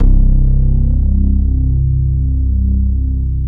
35BASS01  -L.wav